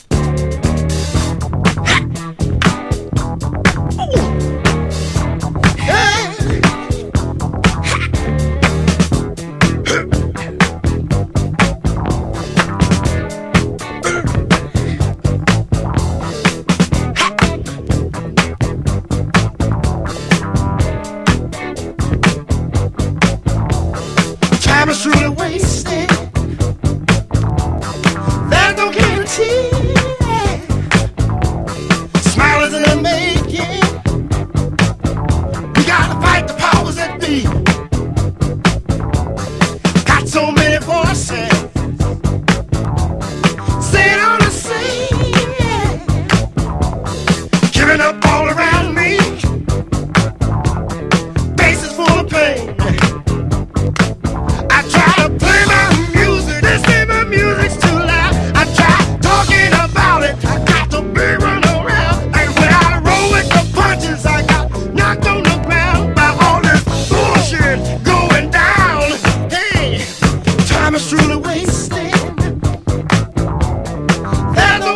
SOUL / SOUL / 60'S / RHYTHM & BLUES